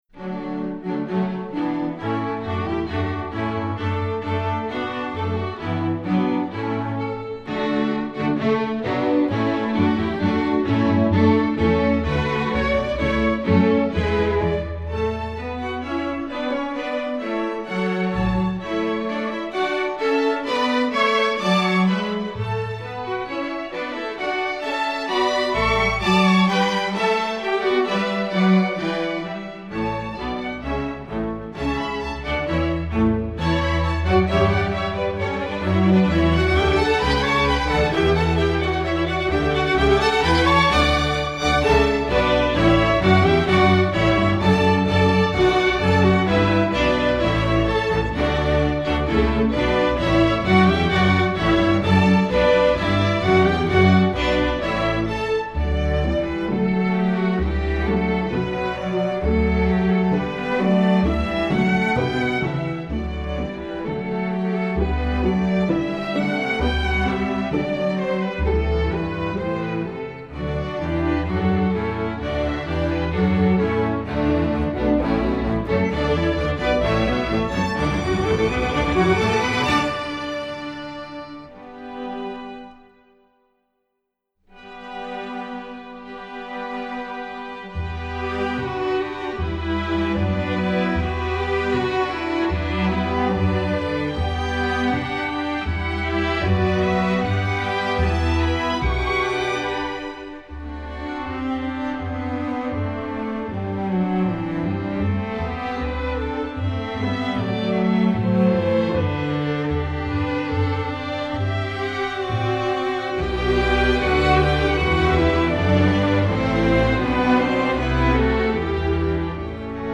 Instrumentation: string orchestra
classical, children